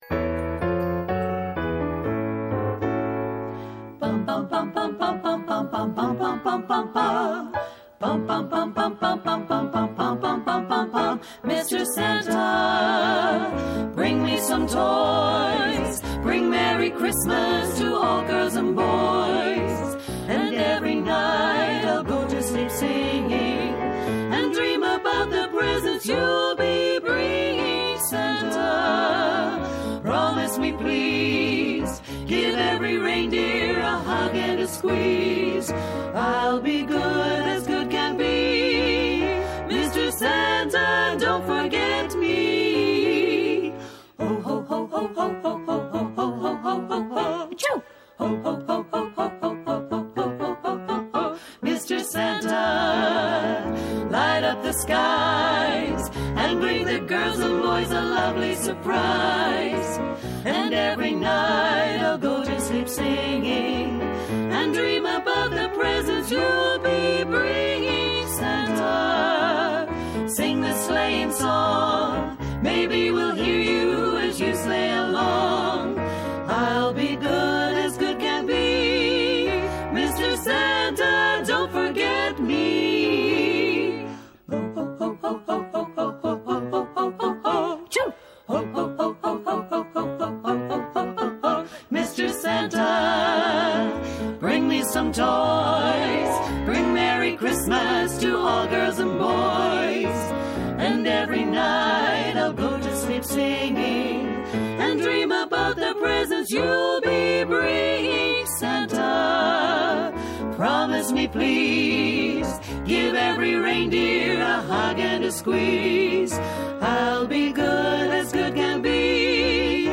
Belfast ladies singing group
keys